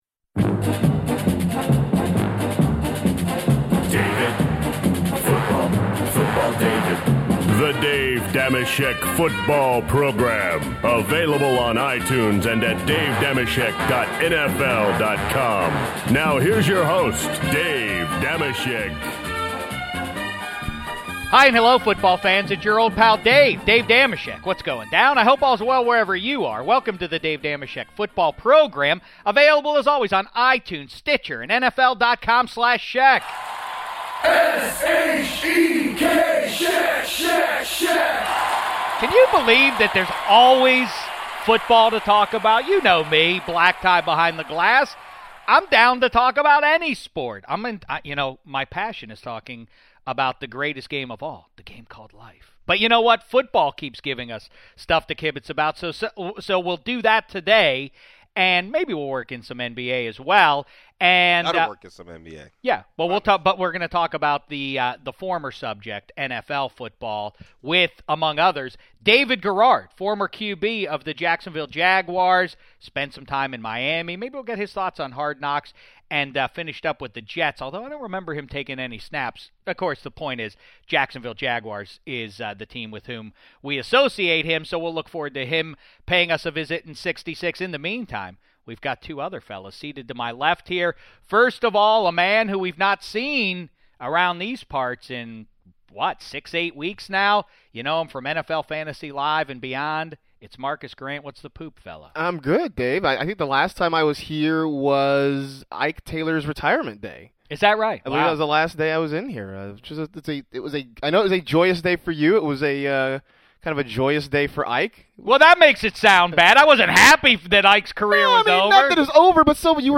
David Garrard also joins the show and the guys give their NBA Finals predictions.